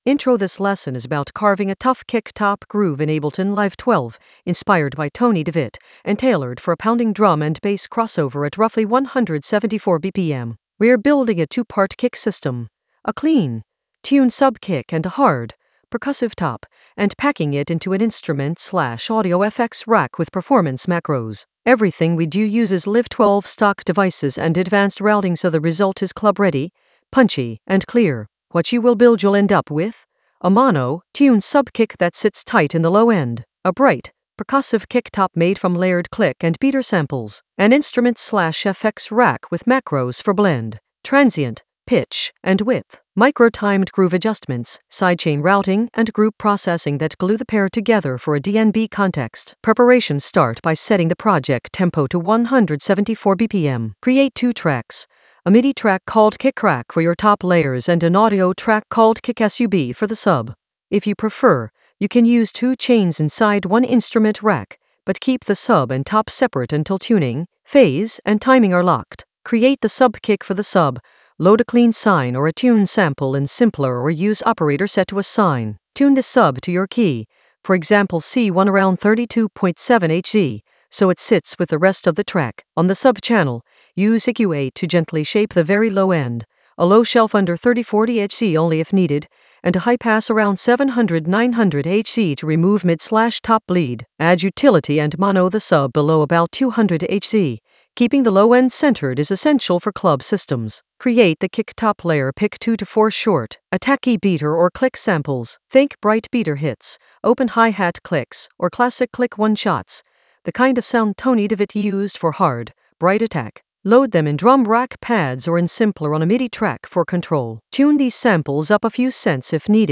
An AI-generated advanced Ableton lesson focused on Tony De Vit influence: carve a tough kick-top groove in Ableton Live 12 for pounding drum and bass crossover in the Drums area of drum and bass production.
Narrated lesson audio
The voice track includes the tutorial plus extra teacher commentary.